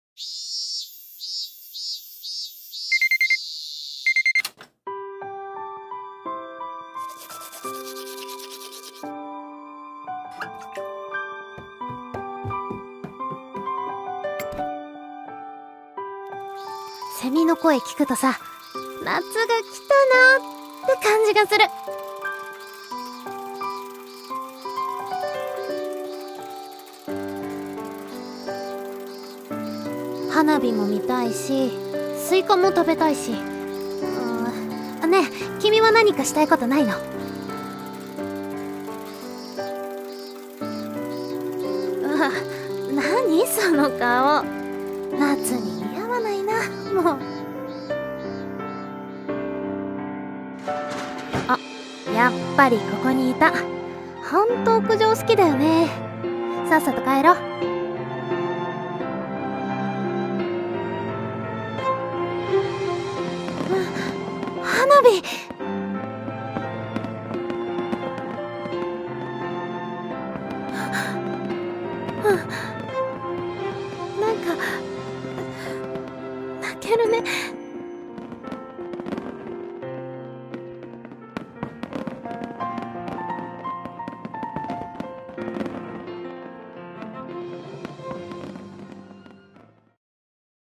【声劇】夏に鳴いたのは、